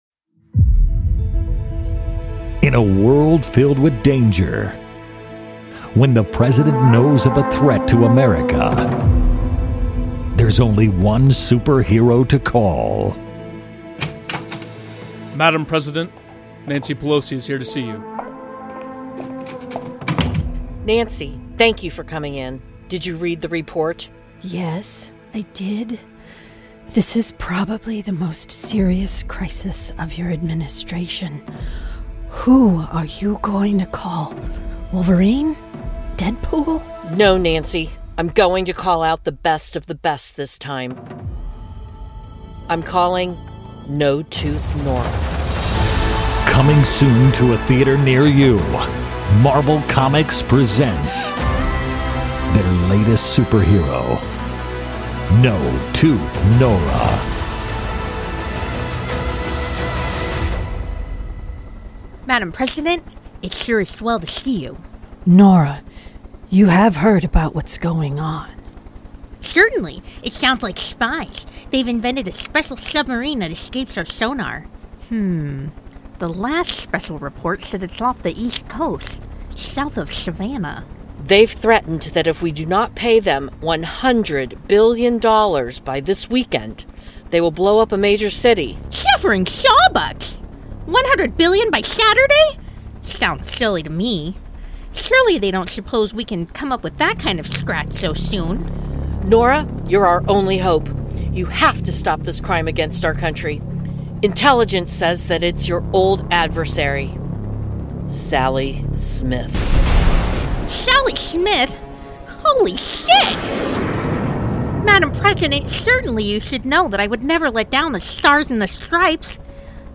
Comedy Audio Drama Synopsis: A comedy podcast with original sketches, satire and sound effects performed by actors who don't get paid.